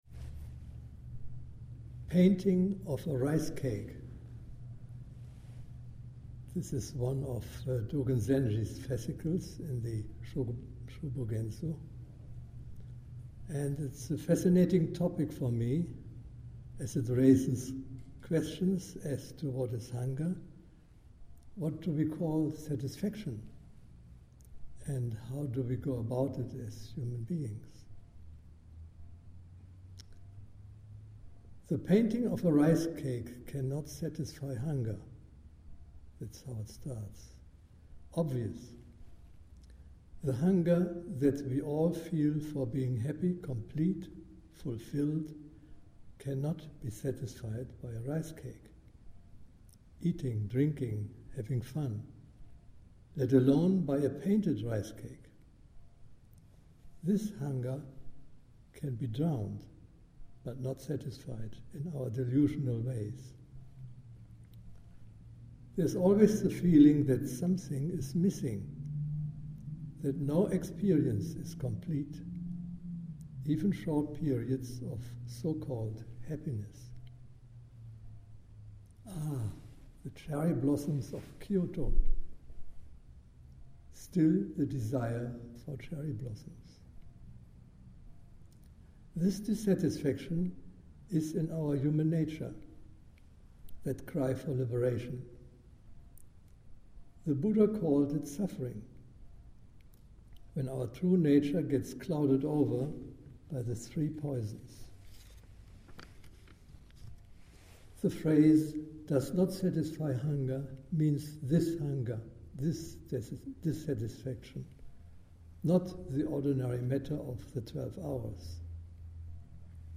Painting of a Ricecake : Dharma Talk